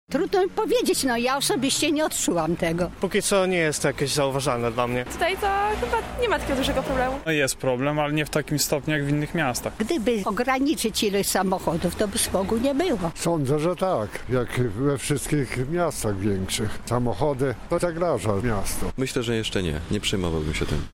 Zapytaliśmy Lublinian, co sądzą na temat smogu w mieście: